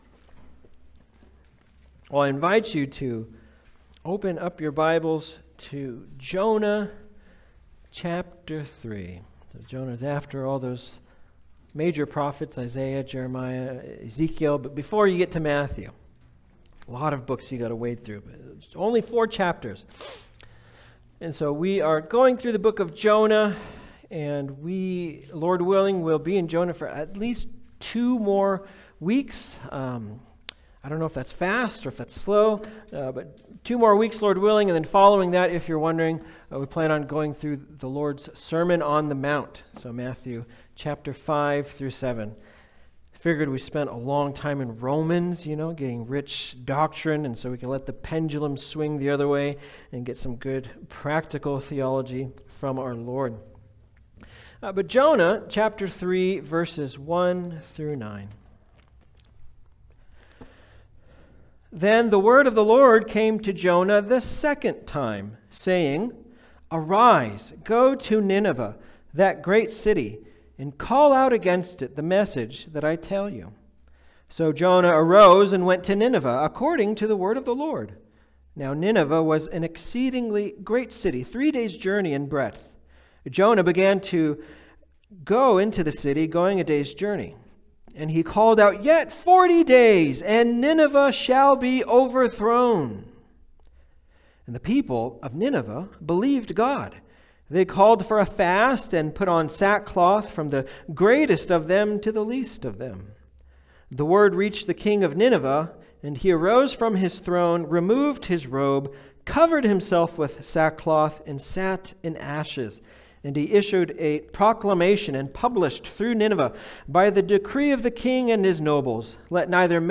Jonah 3:1-9 Service Type: Sunday Service « He Is Risen!